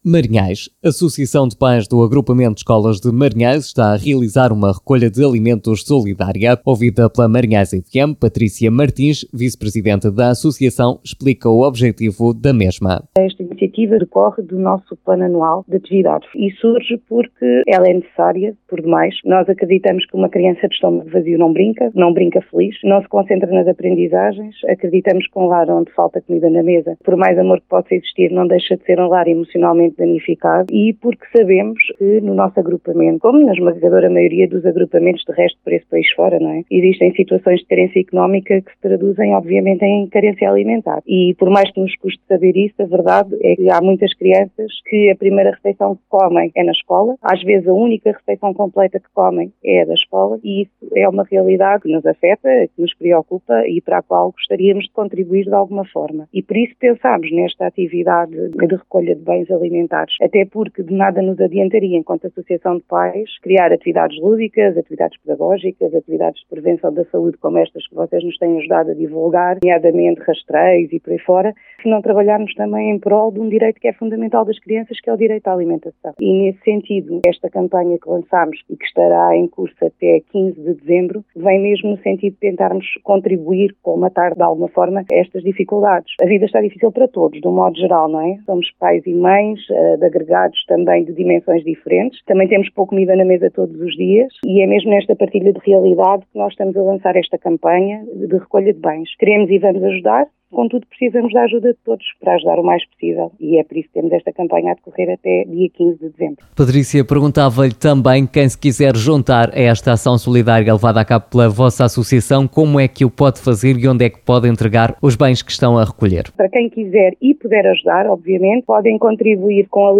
Ouvida pela nossa Rádio